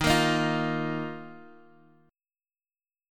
D#sus2 chord